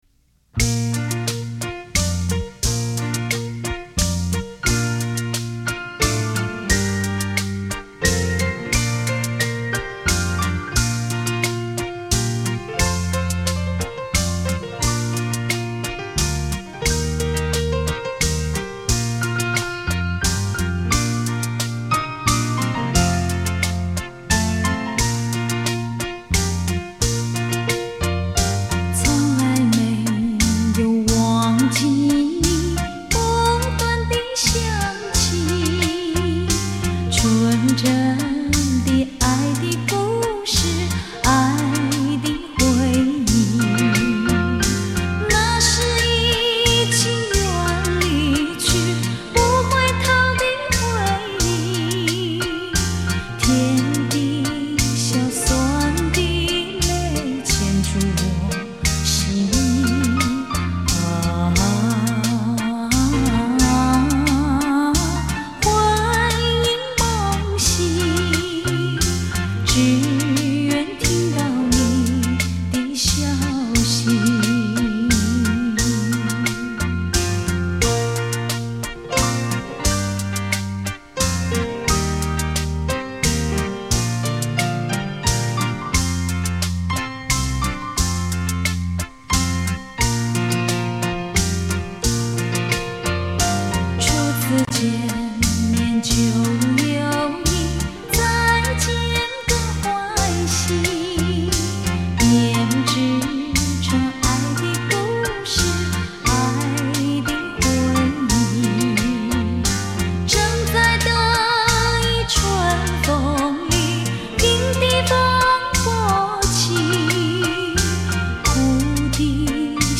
双钢琴伴奏
仑巴